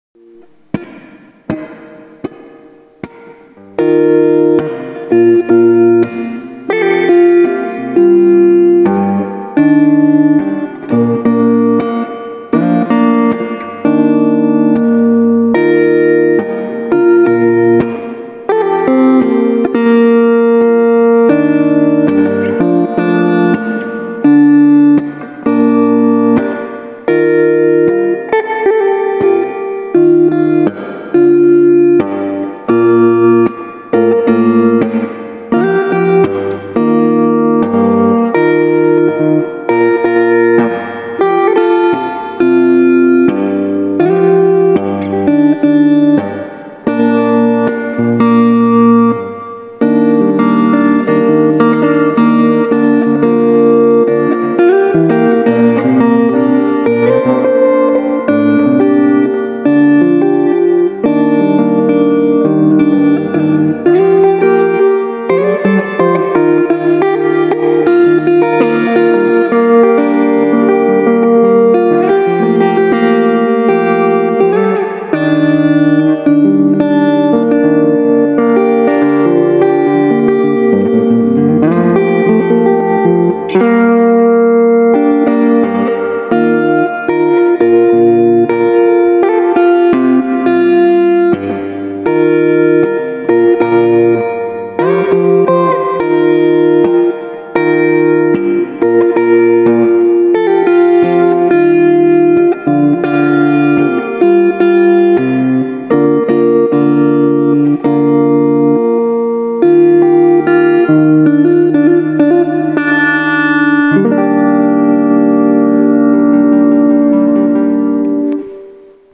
Guitar jam
My guitar.